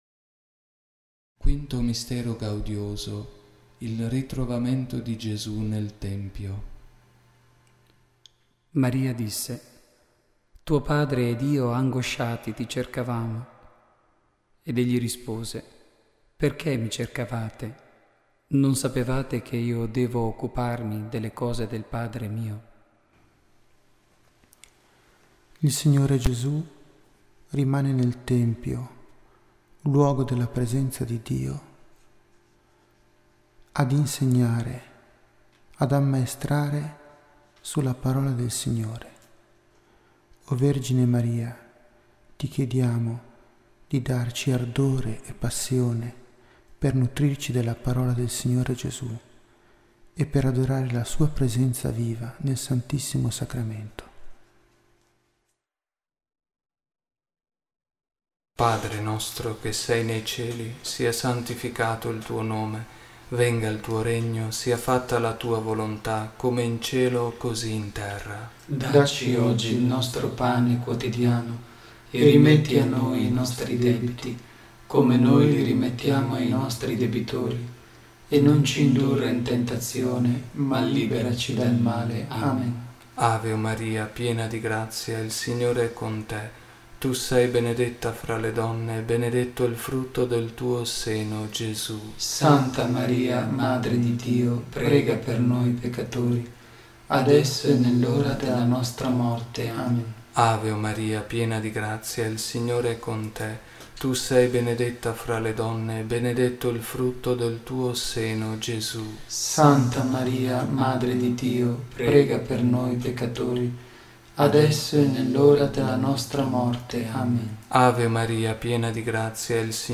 registrazione in studio
Il Santo Rosario in mp3